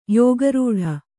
♪ yōga rūḍha